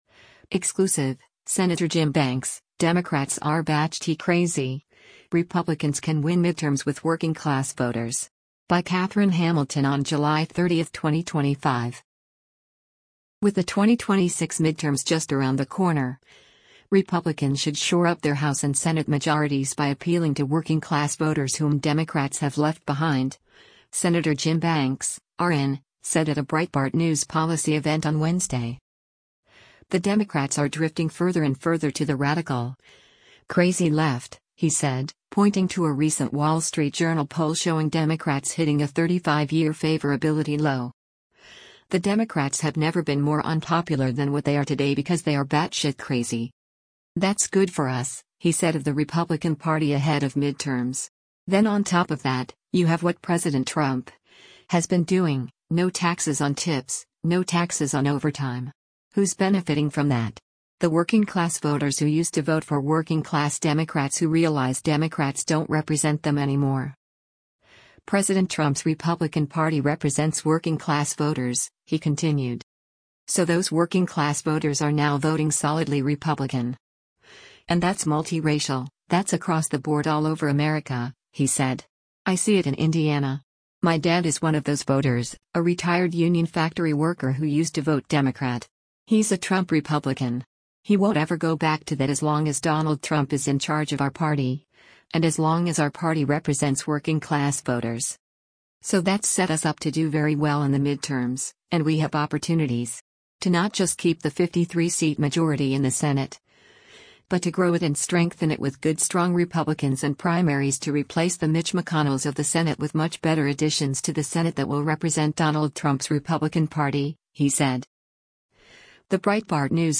With the 2026 midterms just around the corner, Republicans should shore up their House and Senate majorities by appealing to working class voters whom Democrats have left behind, Sen. Jim Banks (R-IN) said at a Breitbart News policy event on Wednesday.